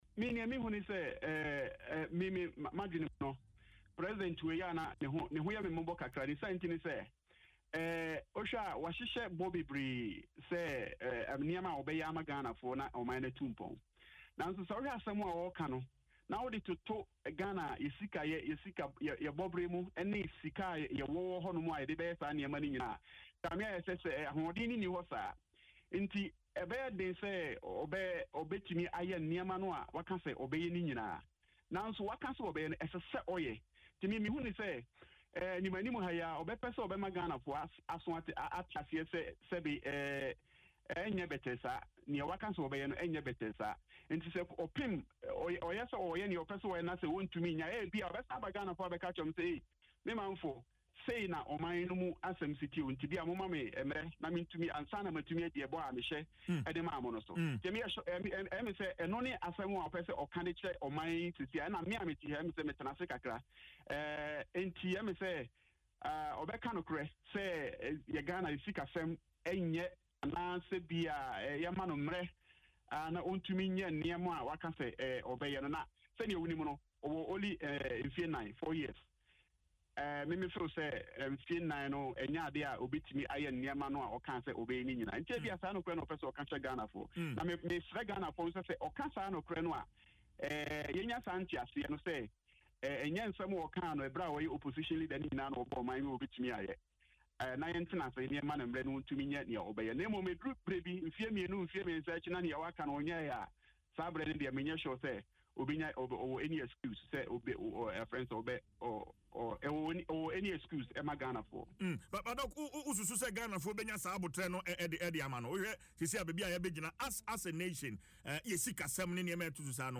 interview on Adom FM Dwaso Nsem